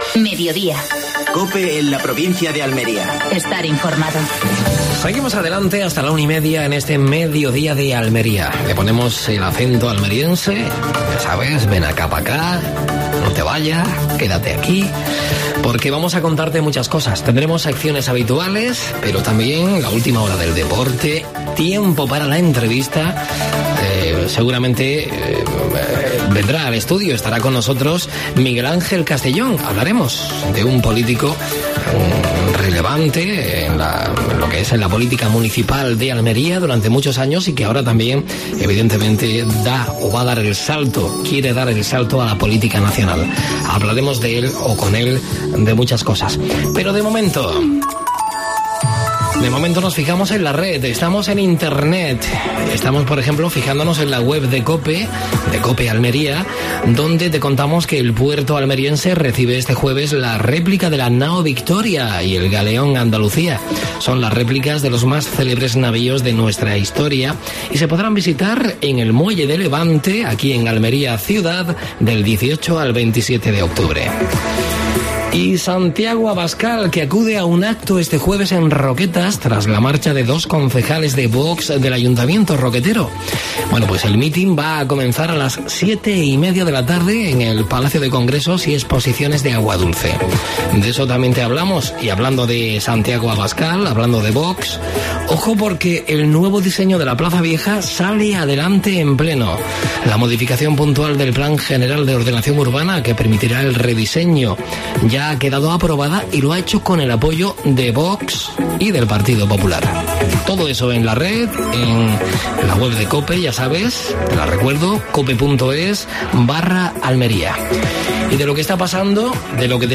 AUDIO: Actualidad en Almería. Entrevista a Miguel Ángel Castellón (candidato nº1 del PP al Congreso de los Diputados).